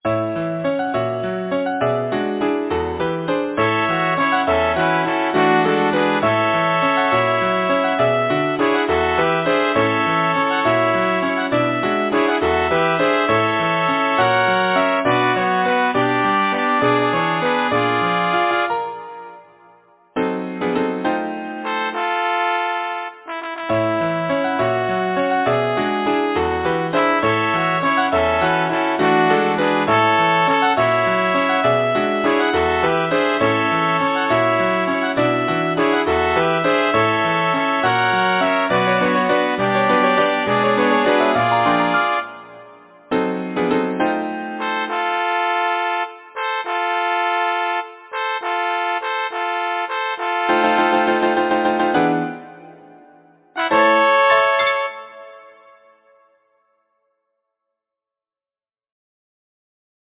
Title: A spring song Composer: Philip James Lyricist: Thomas Nashe Number of voices: 3vv Voicing: SSA Genre: Secular, Partsong
Language: English Instruments: Piano